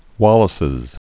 (wŏlĭ-sĭz) or Wal·lace Line (wŏlĭs)